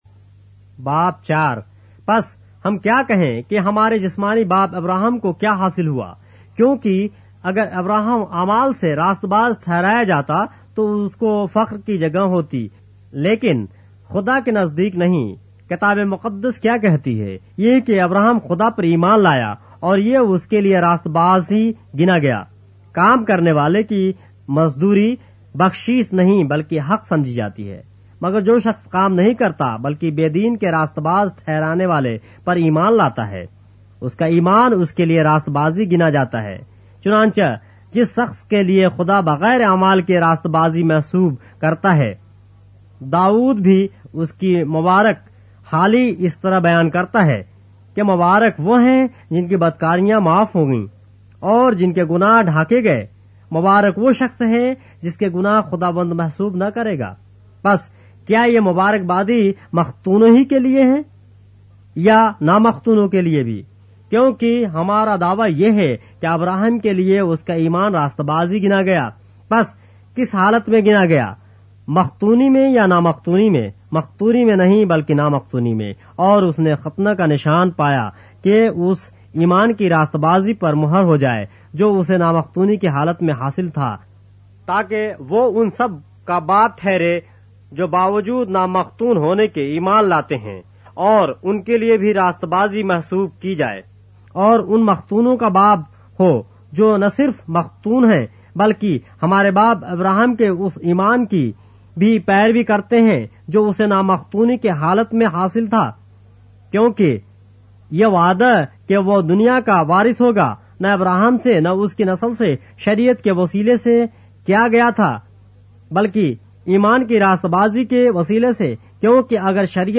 اردو بائبل کے باب - آڈیو روایت کے ساتھ - Romans, chapter 4 of the Holy Bible in Urdu